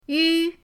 yu1.mp3